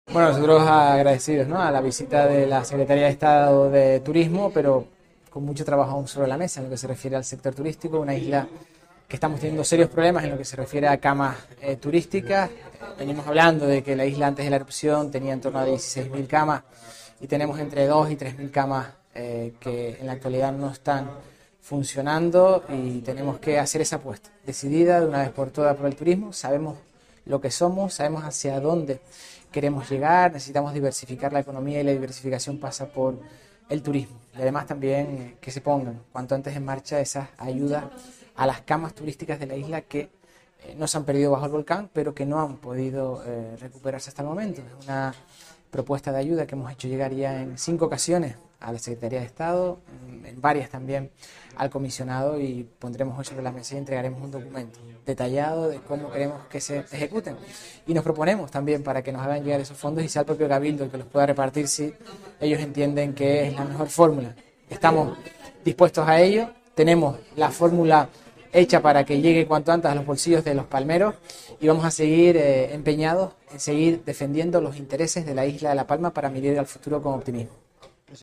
Declaraciones audio Mariano Zapata secretaria Turismo.mp3